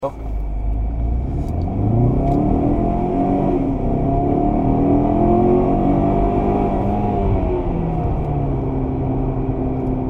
运动模式，转速能干到6000转升档 延迟升档 爽的一比！
传一个0-80运动模式下的发动机声音，还是蛮好听的 哈哈